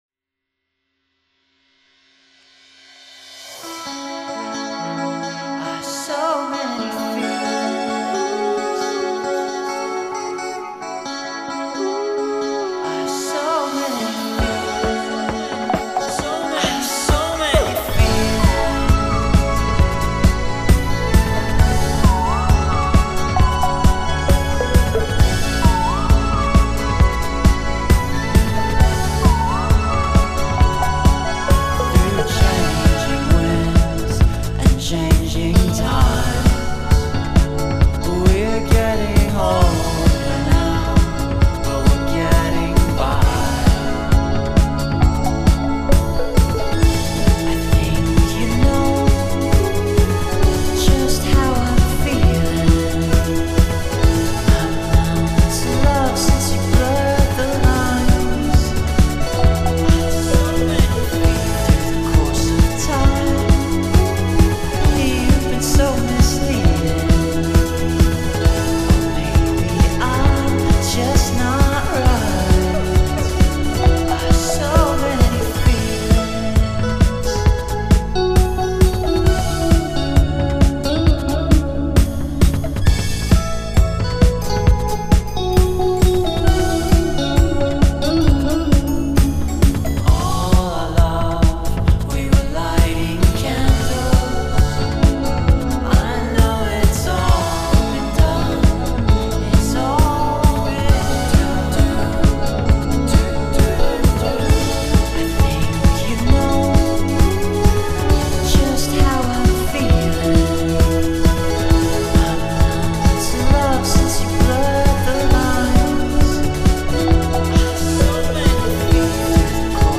dream pop delicato